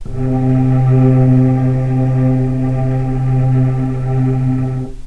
vc-C3-pp.AIF